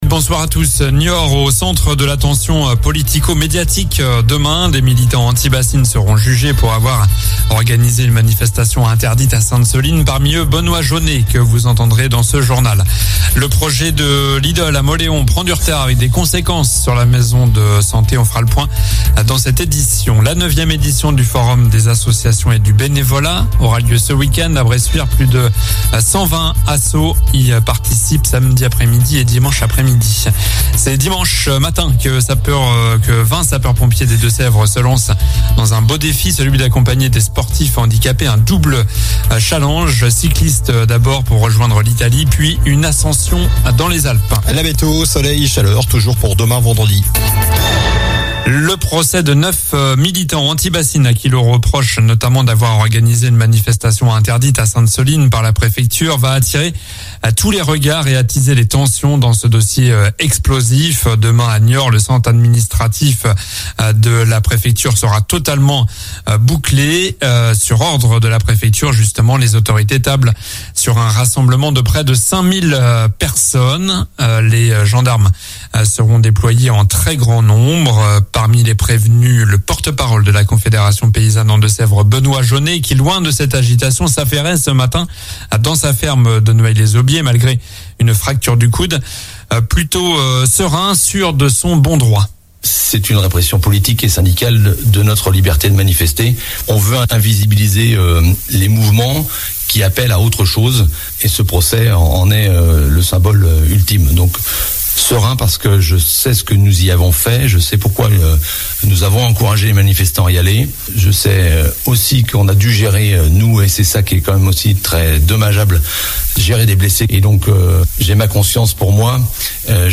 Journal du jeudi 7 septembre (soir)